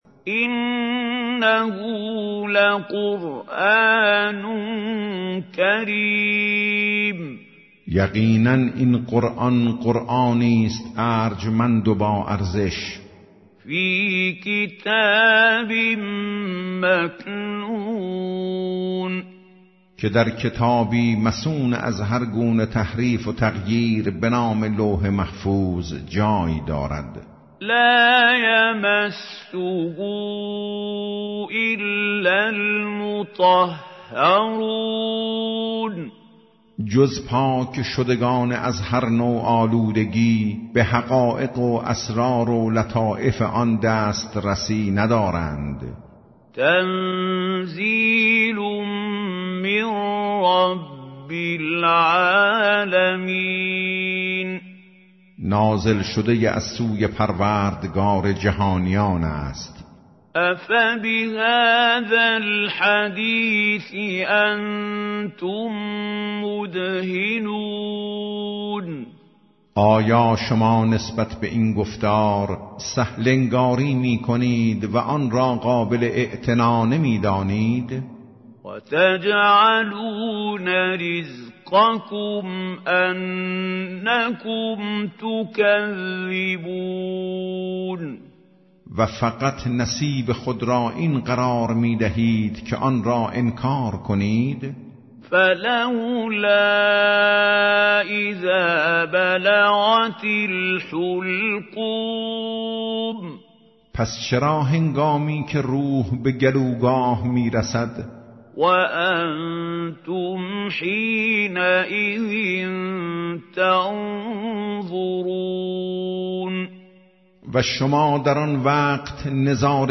ترجمه گویای قرآن کریم - جزء۲۷